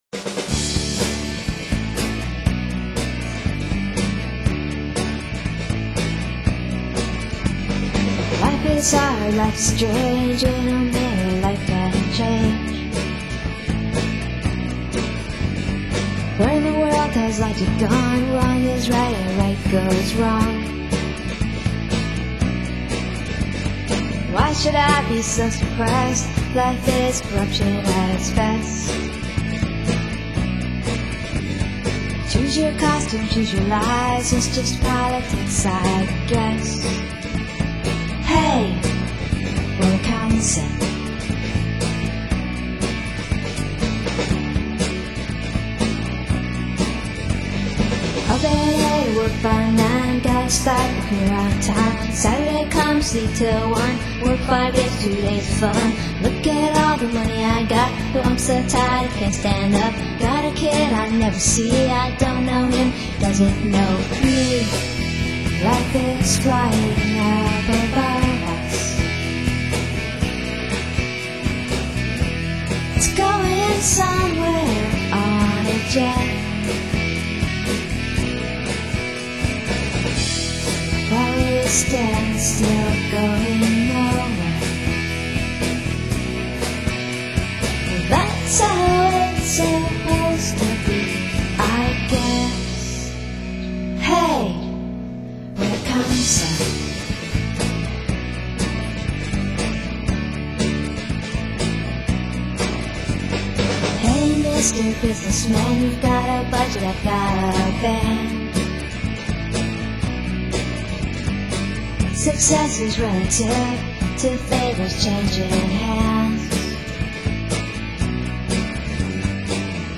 These CDs are raw, real, and highly flawed -
GUITARS AND VOCALS